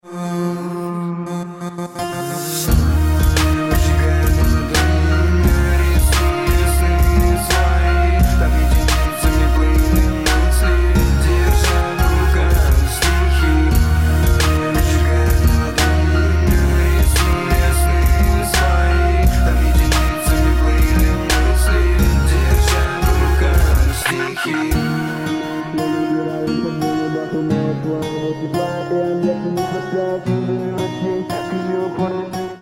• Качество: 128, Stereo
мужской вокал
громкие
русский рэп